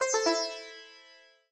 sitar_c1ae.ogg